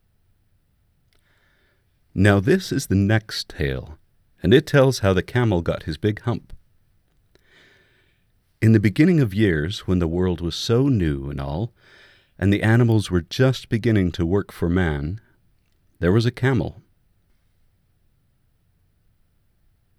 I’m posting a fresh cut to give a better sample of room tone. Same equipment, raw cut.
Good to go even with no noise reduction at all. I get noise at -65.5dB with straight mastering.
When I did the mastering trip, I cut off the last 2-1/2 seconds of silence.